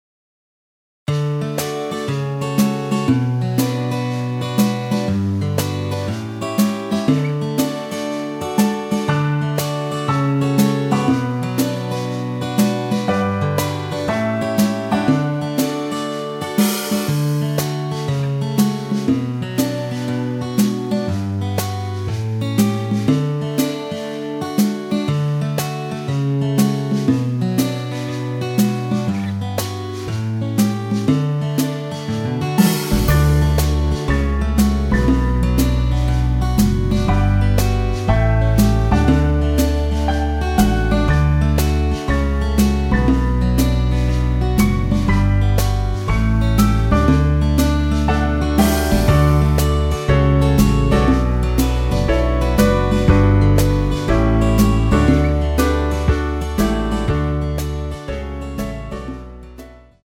엔딩이 페이드 아웃에 너무 길어서 4마디로 엔딩을 만들었습니다.
원키에서(+2)올린 MR 입니다.
Db
앞부분30초, 뒷부분30초씩 편집해서 올려 드리고 있습니다.